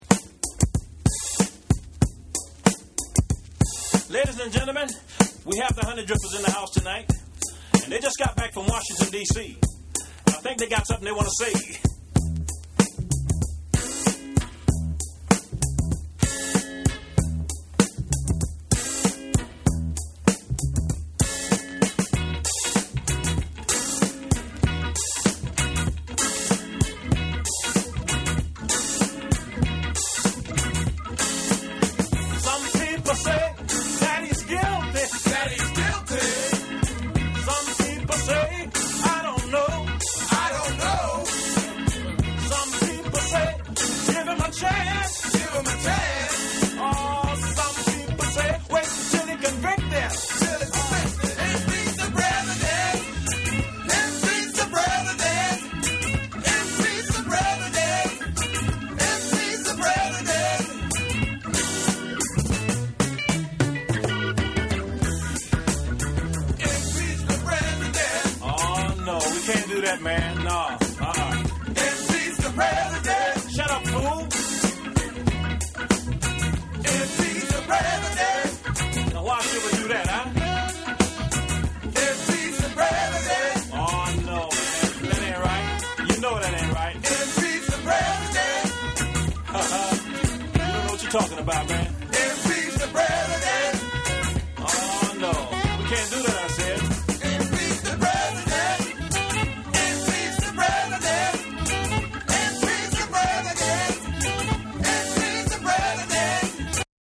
全体をとおして完成度の高いファンキー・チューン